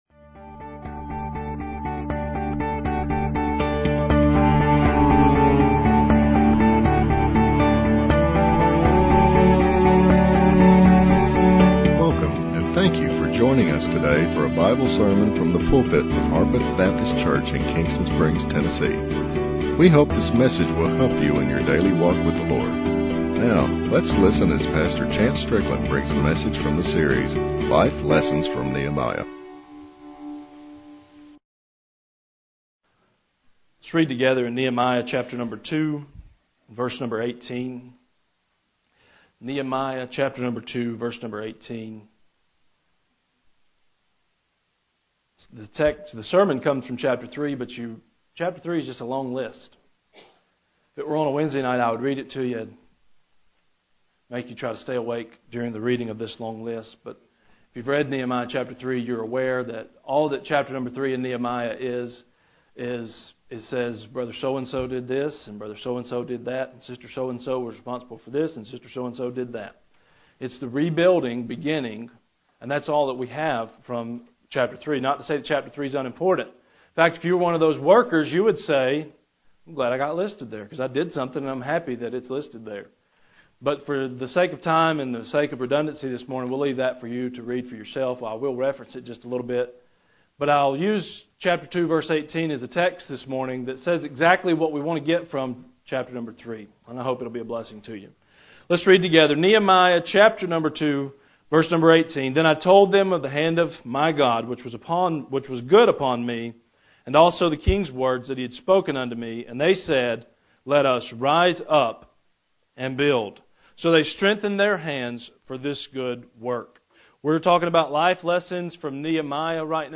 Sermons | Harpeth Baptist Church